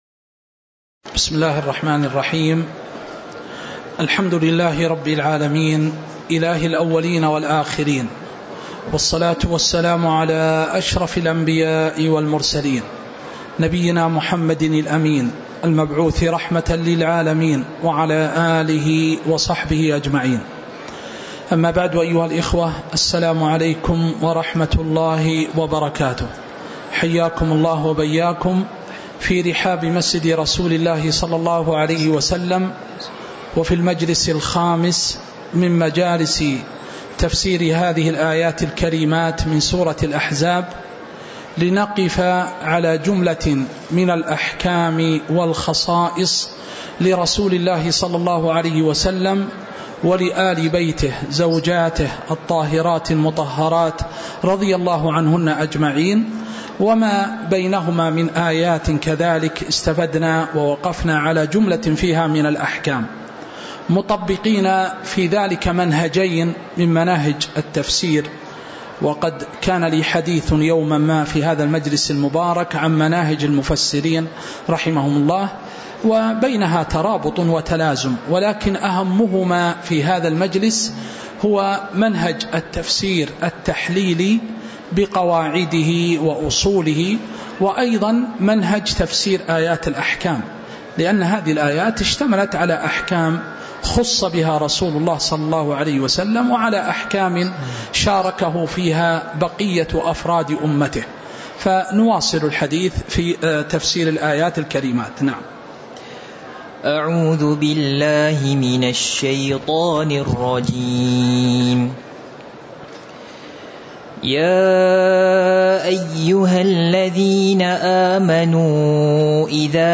تاريخ النشر ٢٨ ربيع الثاني ١٤٤٥ هـ المكان: المسجد النبوي الشيخ